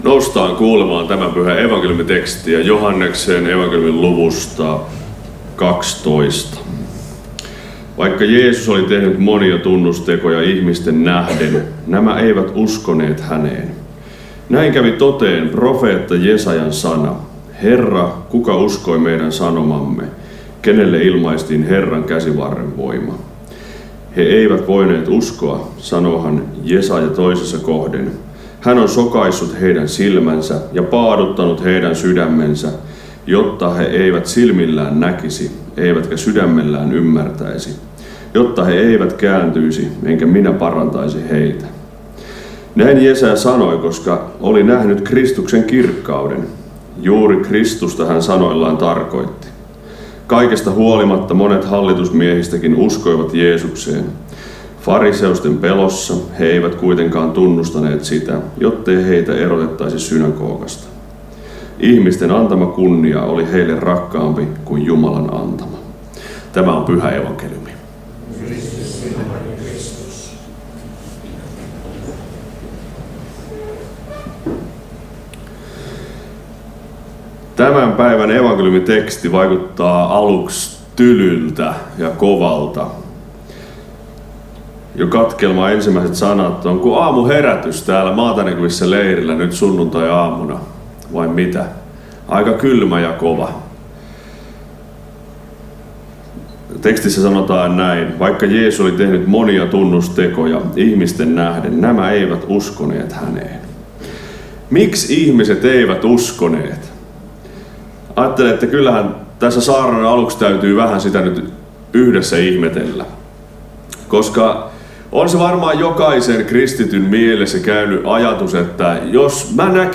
Karkussa 3. paastonajan sunnuntaina